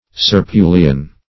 Serpulian \Ser*pu"li*an\